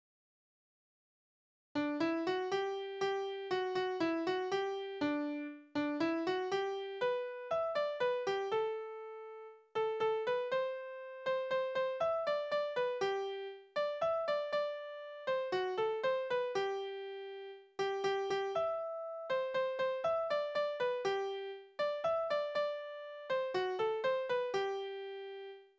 Balladen: Die Rache des verschmähten Liebhabers
Tonart: G-Dur
Taktart: 4/4
Tonumfang: große None
Besetzung: vokal